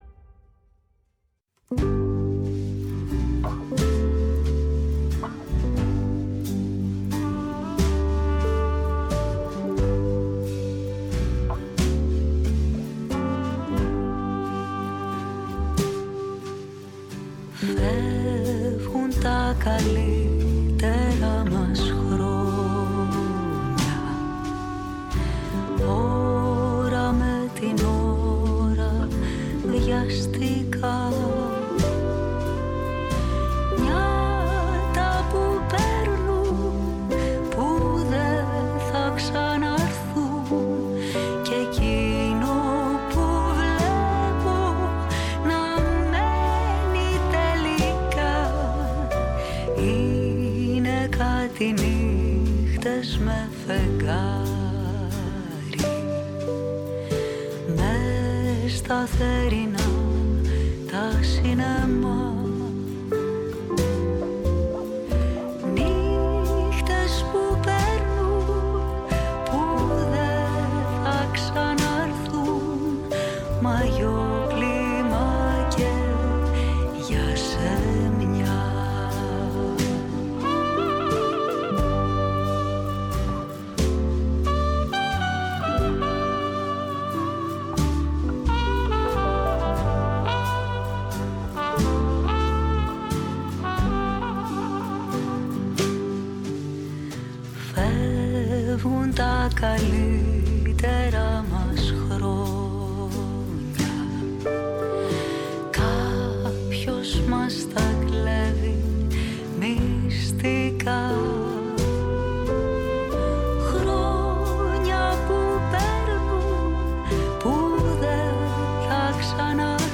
Στο στούντιο της “Φωνής της Ελλάδας” φιλοξενήθηκαν